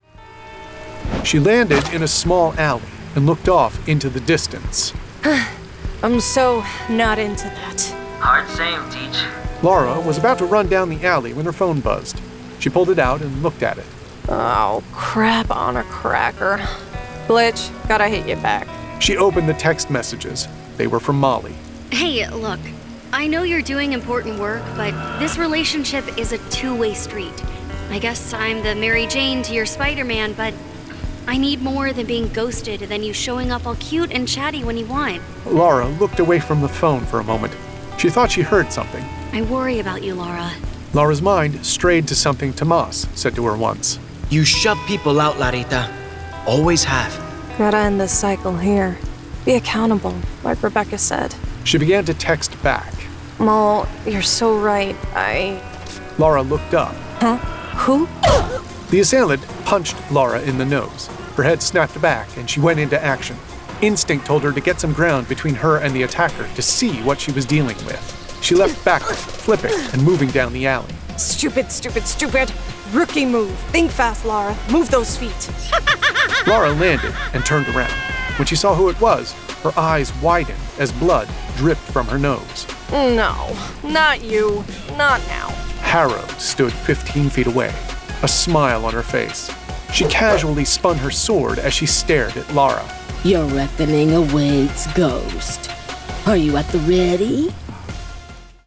Full Cast. Cinematic Music. Sound Effects.
[Dramatized Adaptation]
GraphicAudio presents THE BLACK GHOST produced with a full cast of actors, immersive sound effects and cinematic music!